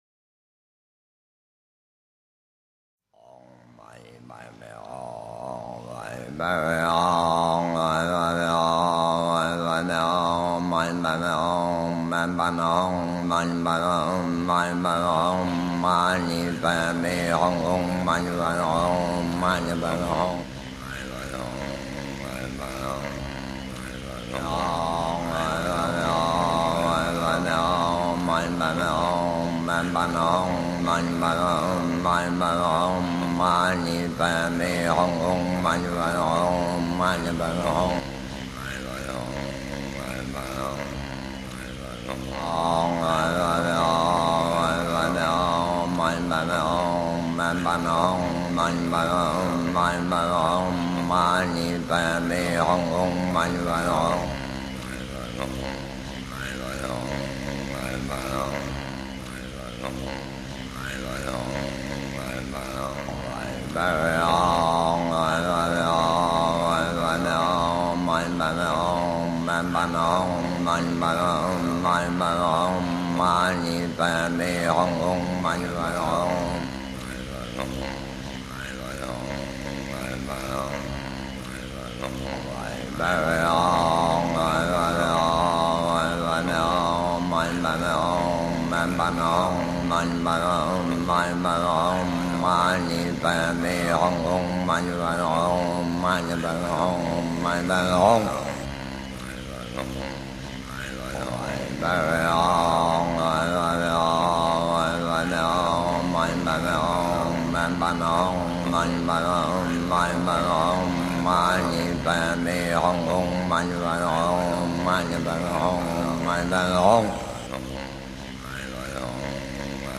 唵（ōng）嘛（mā）呢（nī）叭（bāi）咪（mī）吽（ hòng）
六字大明咒南怀谨老师诵.mp3